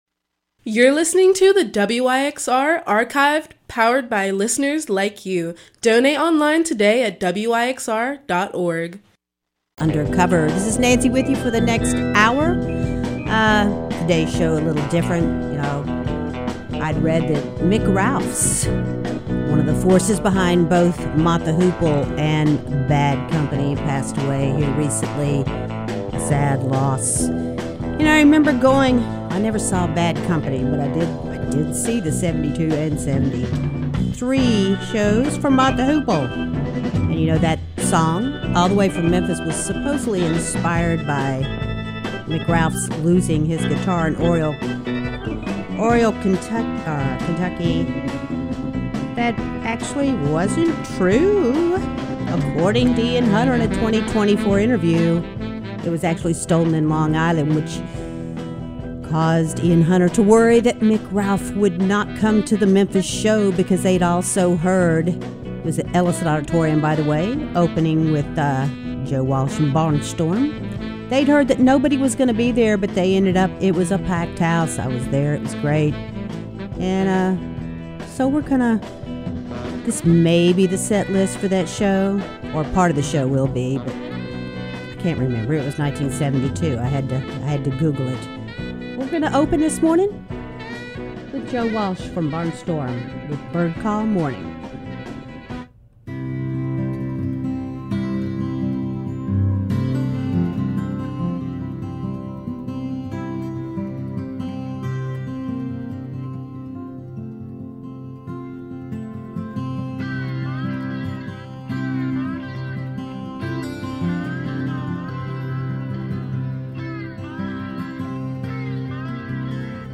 Rock Soul